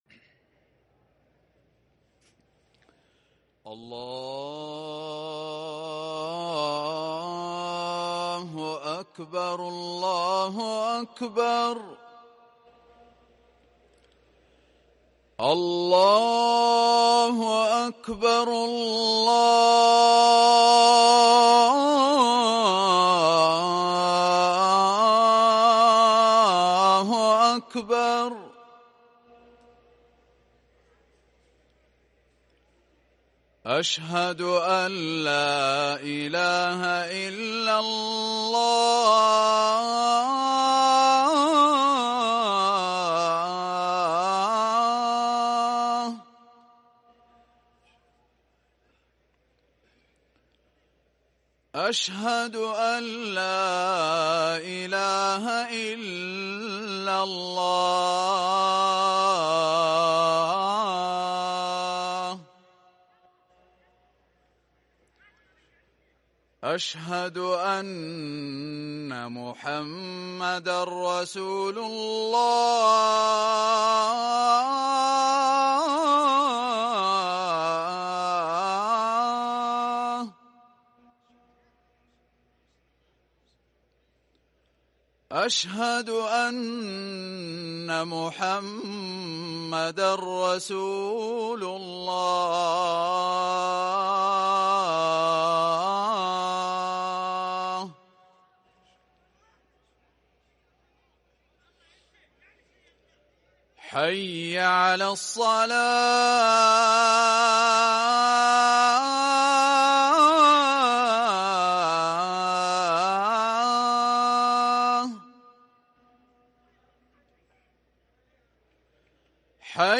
اذان الجمعة الاول للمؤذن محمد العمري الجمعة 7 محرم 1444هـ > ١٤٤٤ 🕋 > ركن الأذان 🕋 > المزيد - تلاوات الحرمين